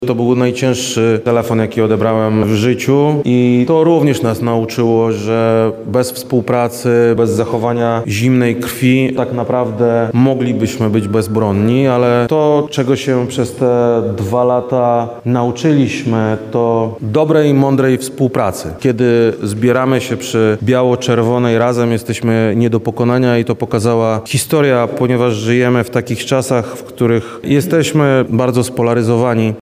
– Najtrudniejszym momentem w mojej karierze była informacja o naruszeniu polskiej przestrzeni powietrznej przez rosyjskie drony – mówi wojewoda Krzysztof Komorski.